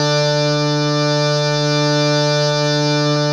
52-key07-harm-d3.wav